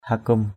/ha-kum/ (cv.) hukum h~k~’ 1.
hakum.mp3